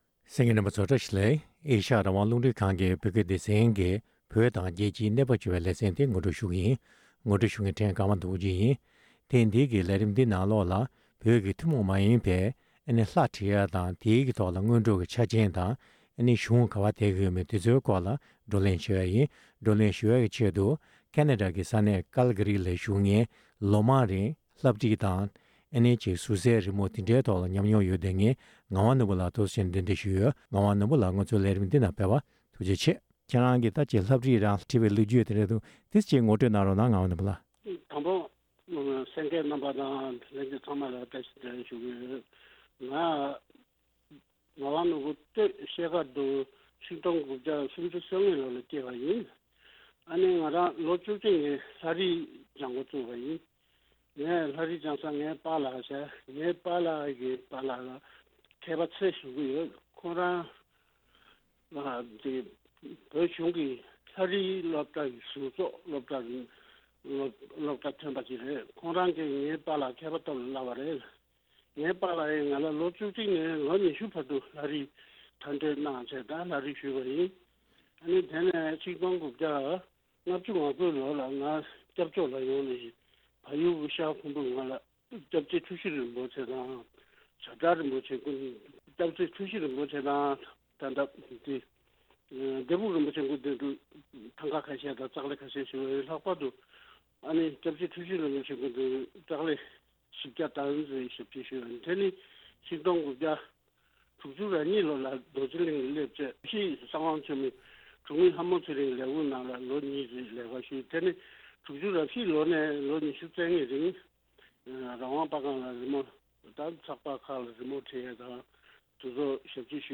བར་གླེང་མོལ་གནང་བར་གསན་རོགས་ཞུ༎